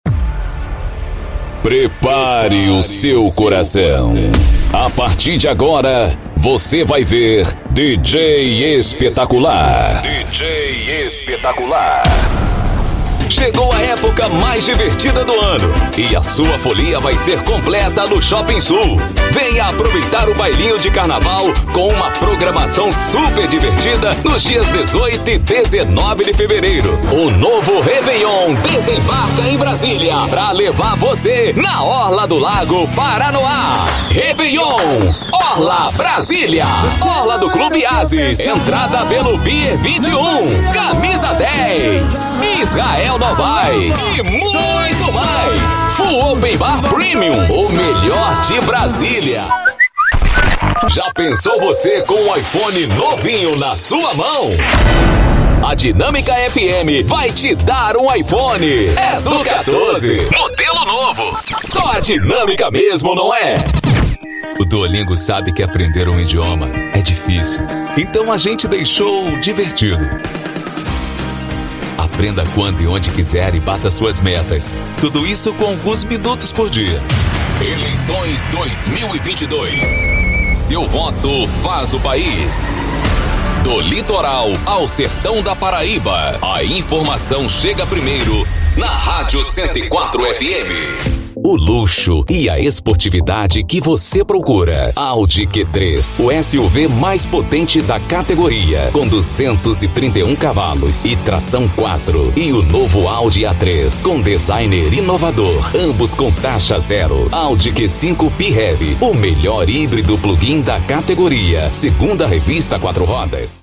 Spot Comercial
Vinhetas
Impacto
Animada